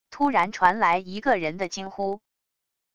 突然传来一个人的惊呼wav音频